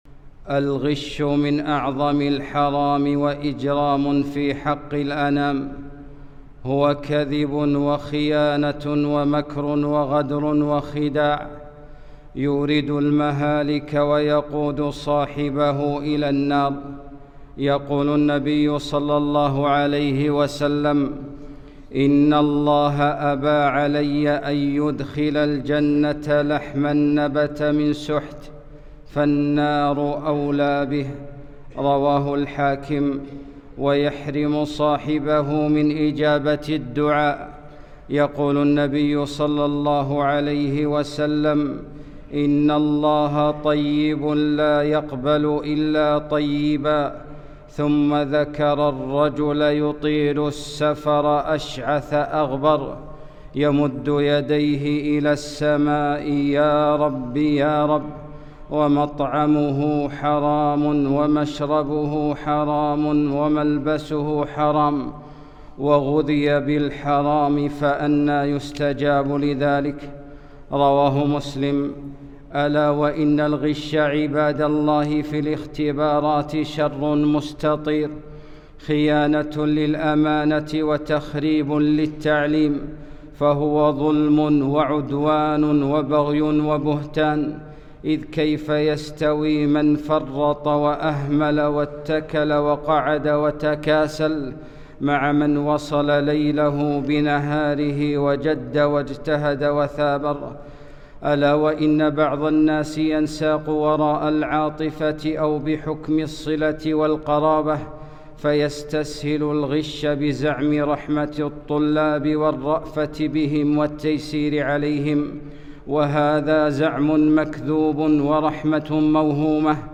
خطبة - مُدمِّر الأجيال ومُخرِّب الديار